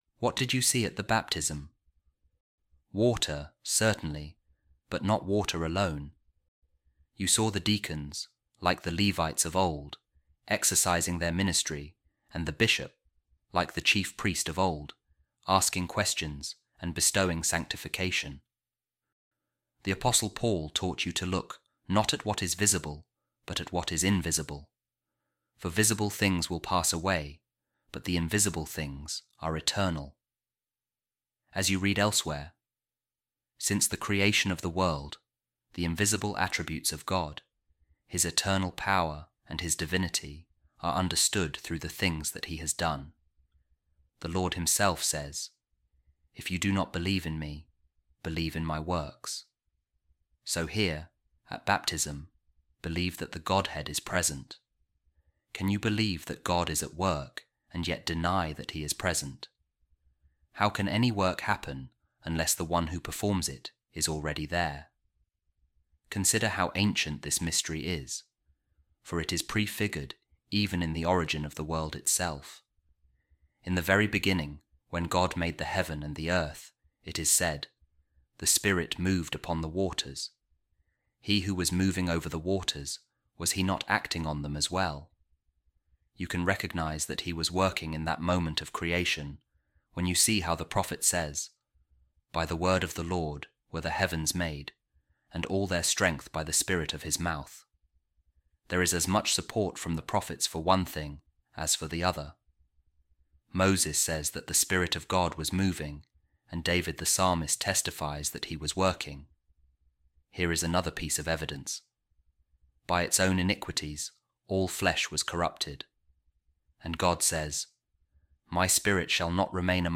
A Reading From The Treatise Of Saint Ambrose On The Mysteries | We Are Born Again Of Water And The Holy Spirit